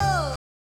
Closed Hats
VOX OHHH.wav